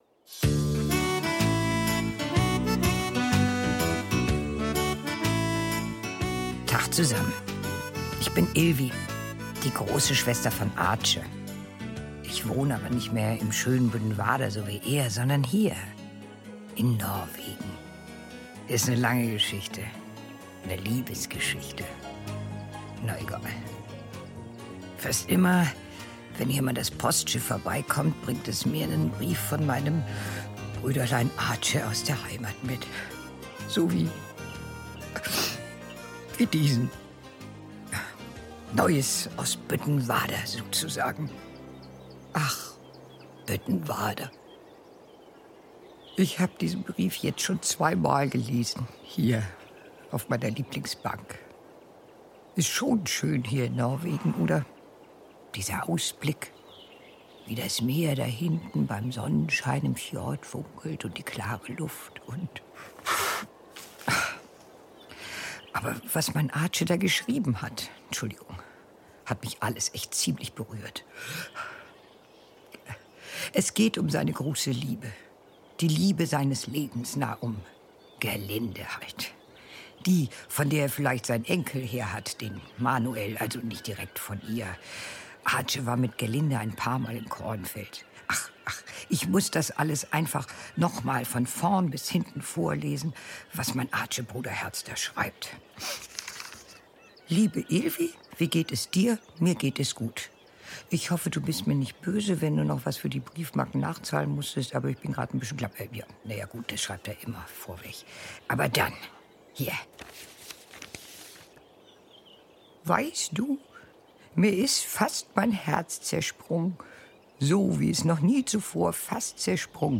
Büttenwarder-Hörspiel: Gerlinde ~ Neues aus Büttenwarder Podcast